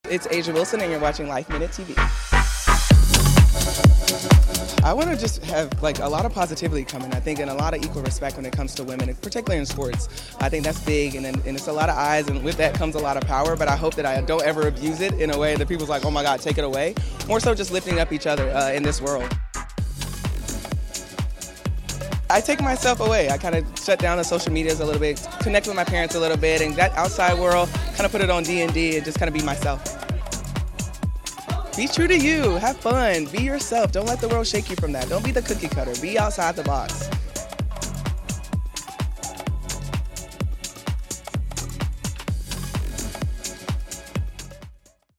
We caught up with the Las Vegas Aces star at the mag’s gala to honor her. We found out how she wants to use her influence to lift up others and how she relaxes when not tearing up the court.